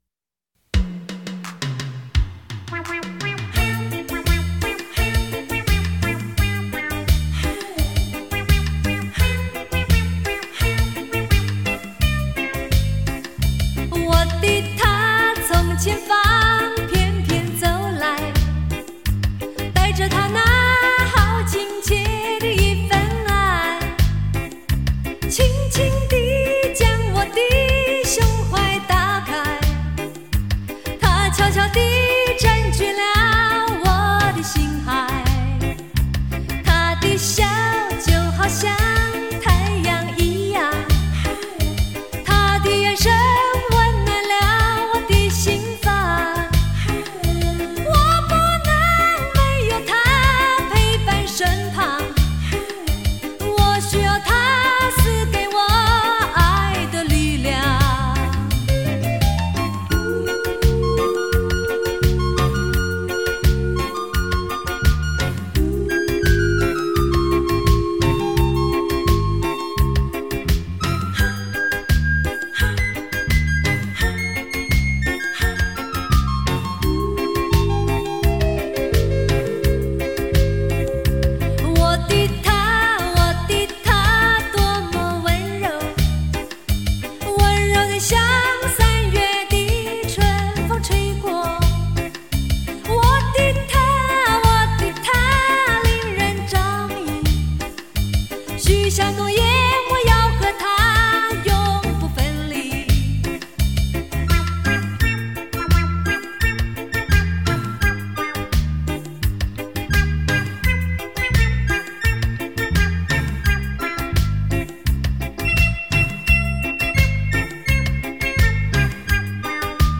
舞厅规格
翅仔舞歌唱版
将自己投入感性的歌声中